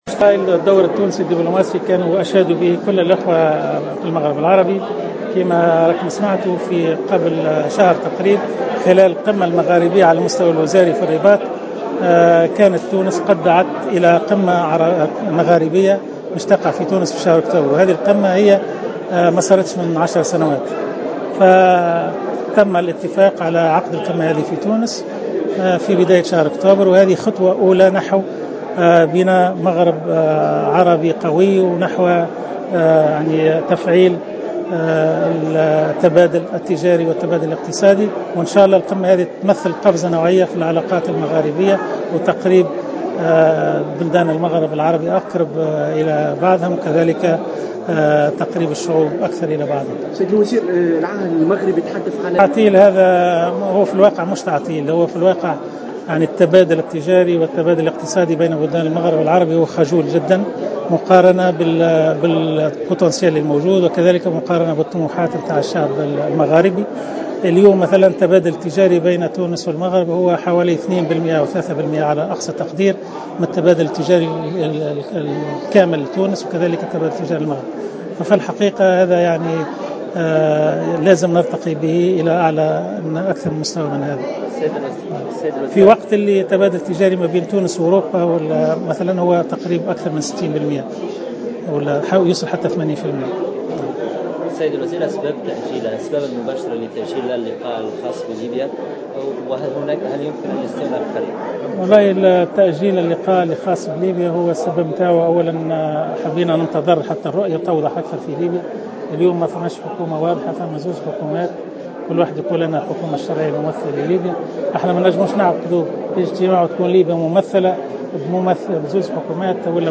أكد وزير الشؤون الخارجية منجي الحامدي في تصريح إعلامي اليوم أنه تم الاتفاق على عقد قمة مغاربية في تونس بداية شهر أكتوبر المقبل لأول مرة منذ 10 سنوات معتبرا ذلك خطوة نحو بناء مغرب عربي قوي, وذلك على هامش الجلسة العامة الممتازة التي عقدت اليوم بالمجلس الوطني التأسيسي بحضور رئيس الجمهورية المنصف المرزوقي وملك المغرب محمد السادس والوفد المرافق له ومجموعة من رجال أعمال والسياسيين